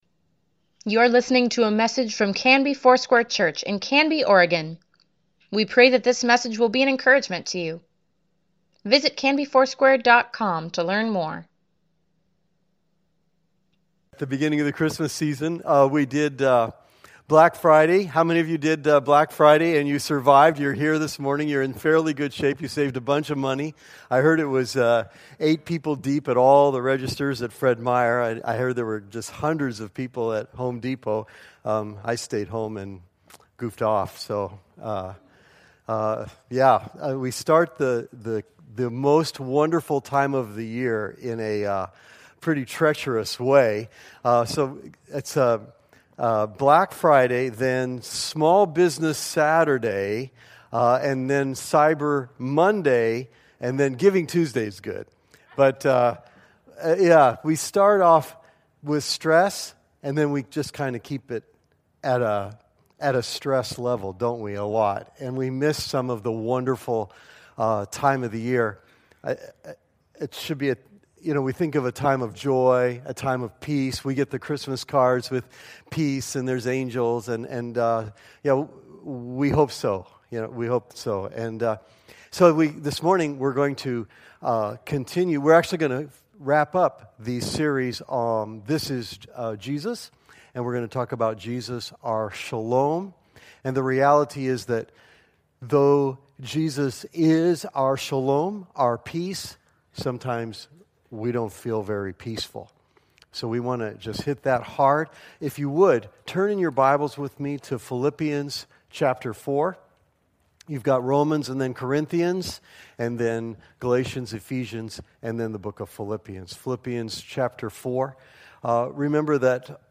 Weekly Email Water Baptism Prayer Events Sermons Give Care for Carus This is Jesus: He is Our Shalom November 26, 2017